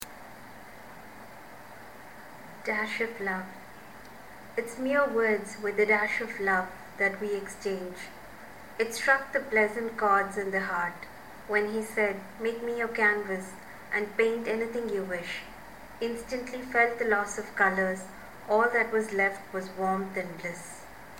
An Old poem... just voiced....